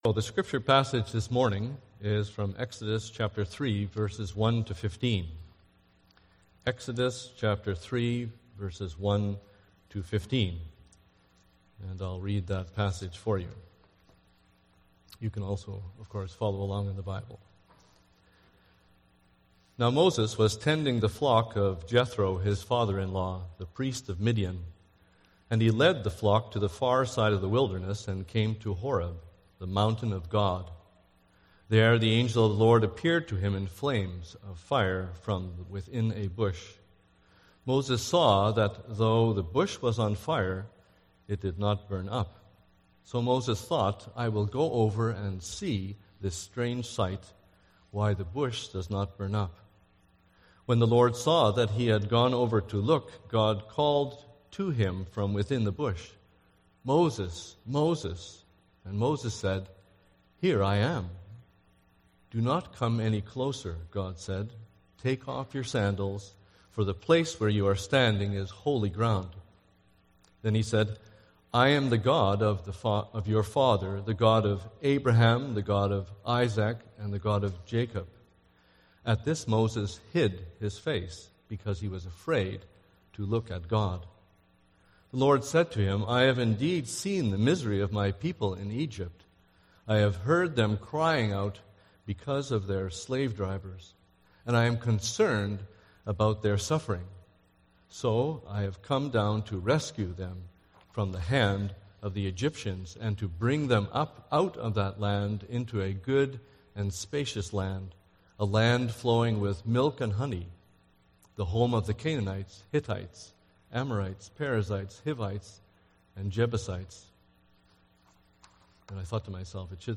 Sermons – Duncan CRC